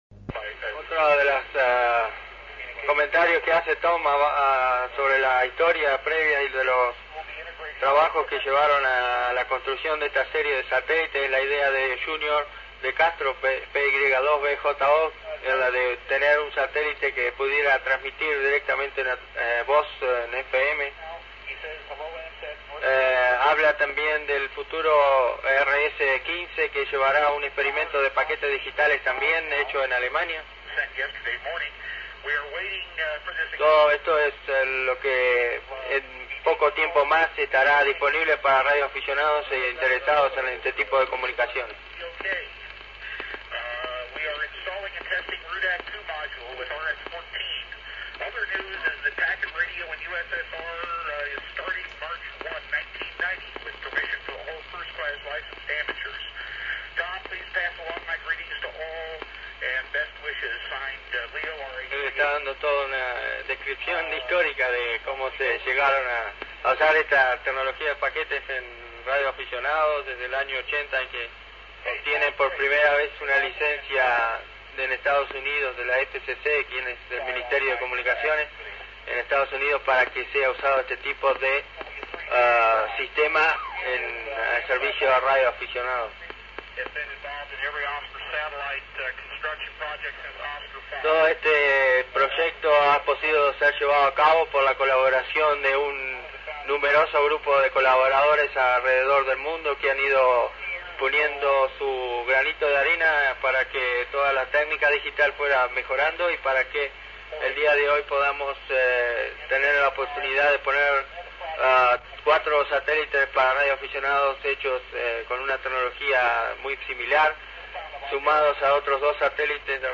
Grabación de radio FM del Sol, 98.1 MHz
Transmisión especial durante el lanzamiento y primera órbita del LUSAT-1.